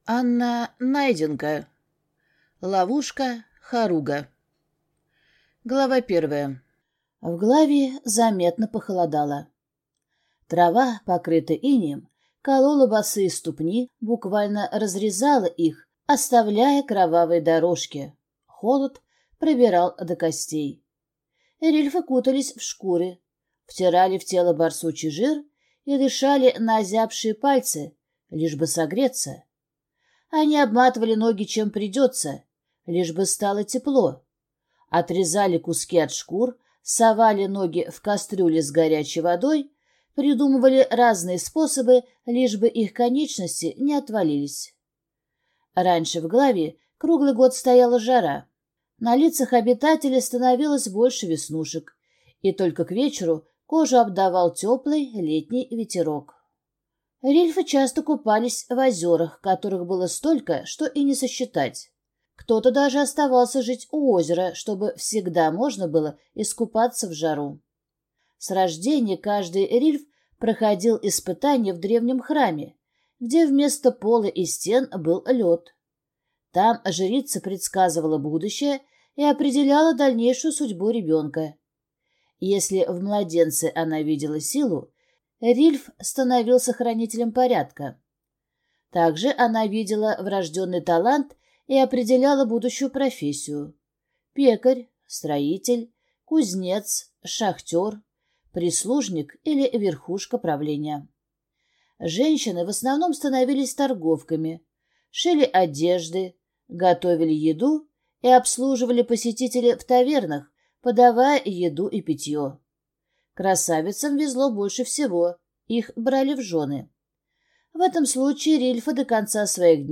Aудиокнига Ловушка Харуга